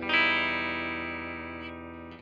007CHORDS2.wav